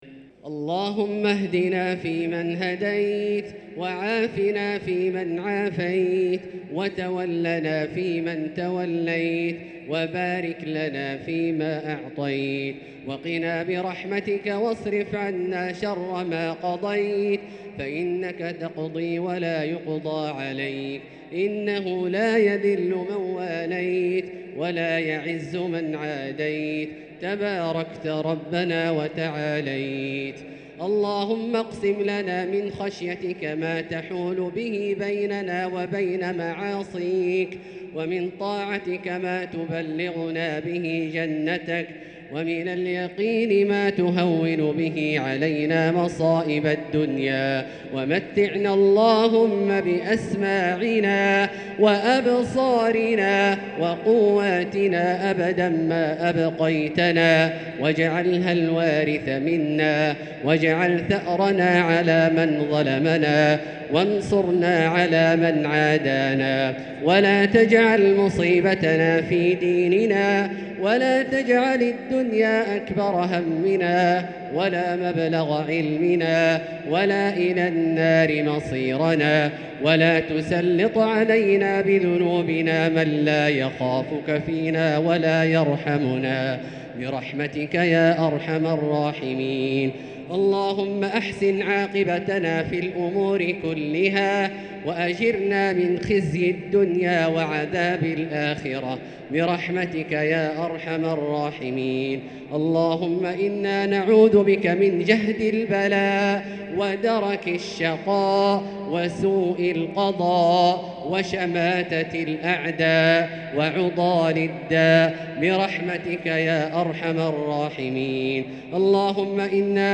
دعاء القنوت ليلة 7 رمضان 1444هـ | Dua 7 st night Ramadan 1444H > تراويح الحرم المكي عام 1444 🕋 > التراويح - تلاوات الحرمين